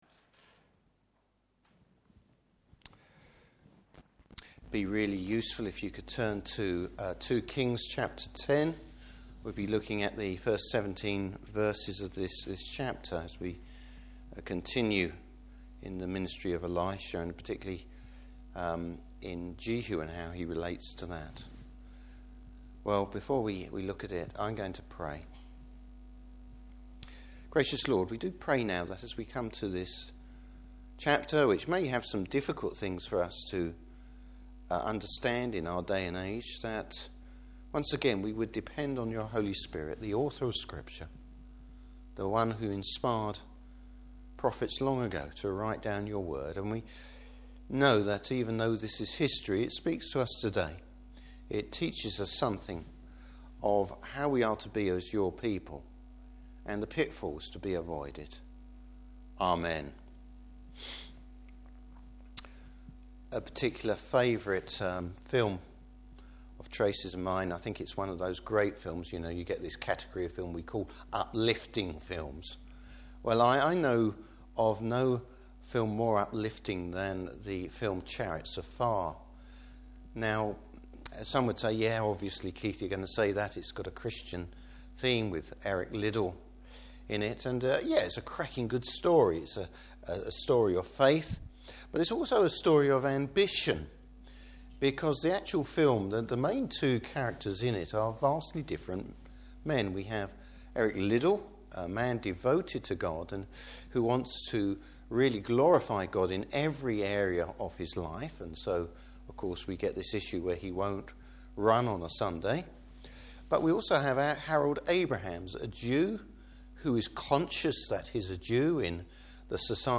Service Type: Evening Service Bible Text: 2 Kings 10:1-17.